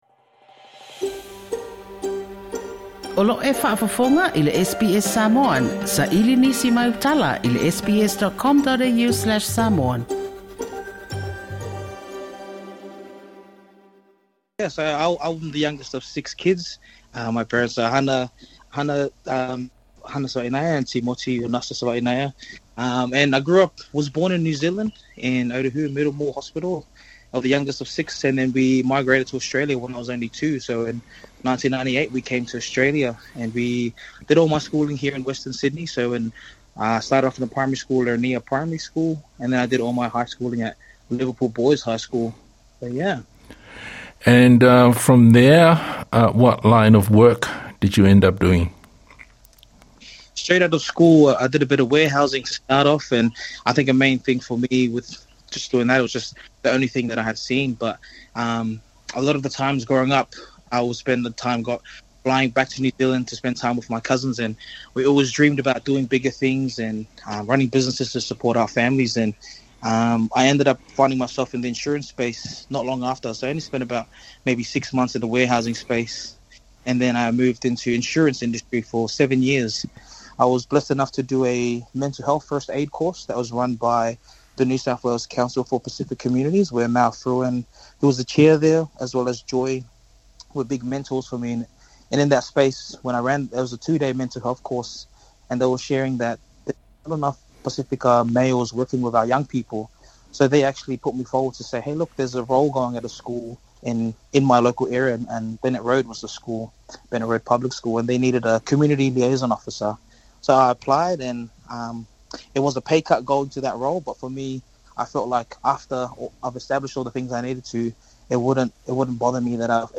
He told me in this interview that an invitation to a community forum changed the course of his life when he was asked to be the liaison officer at a primary school in his local area.